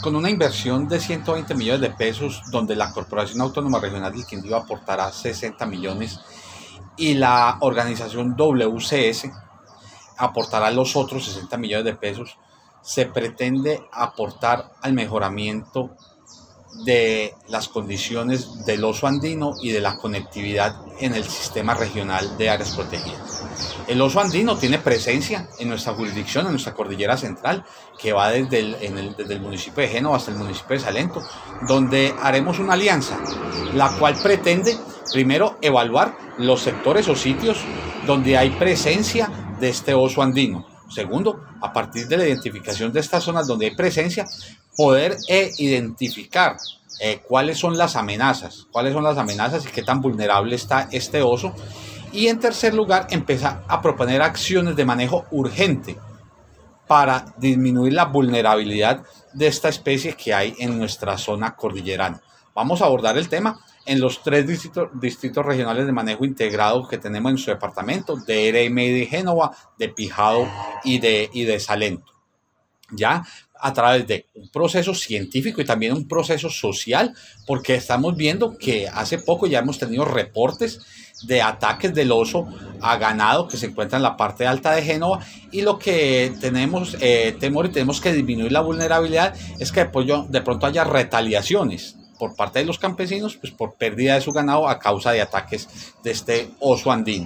AUDIO: DIRECTOR GENERAL DE LA CRQ- JOSÉ MANUEL CORTÉS OROZCO